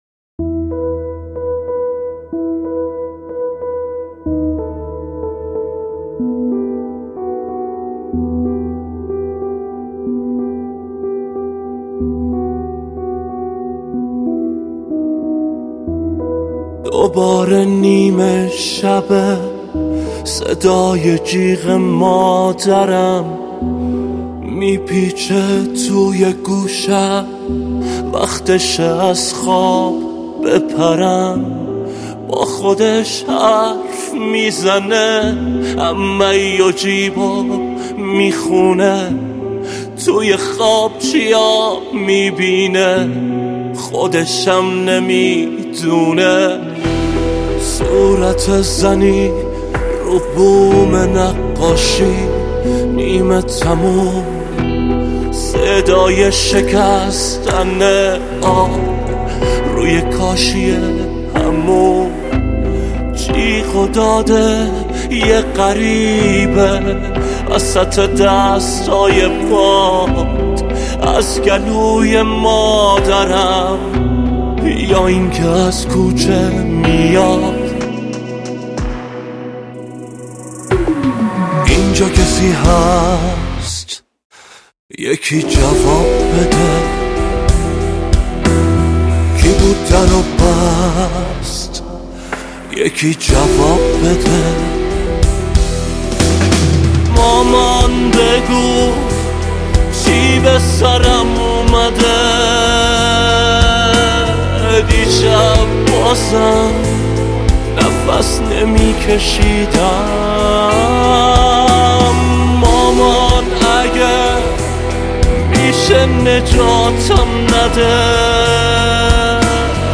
آهنگ غمگین جدید